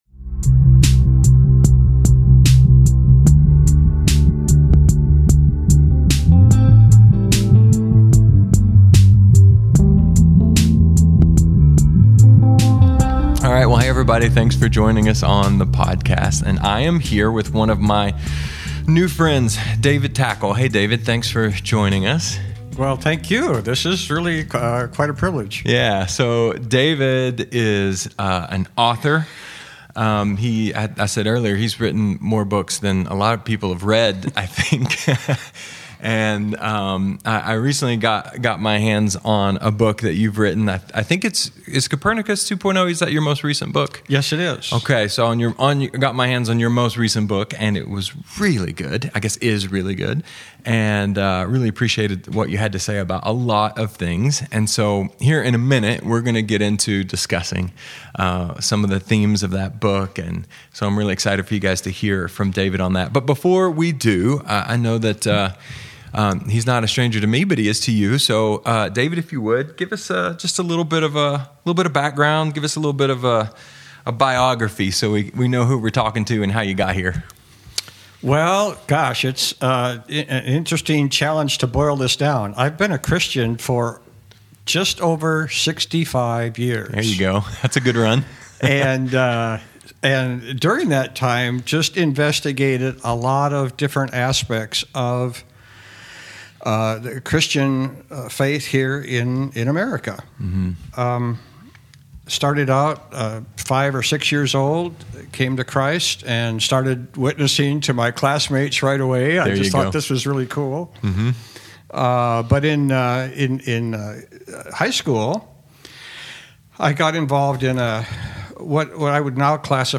Related Topics: conversations | More Messages from Various Speakers | Download Audio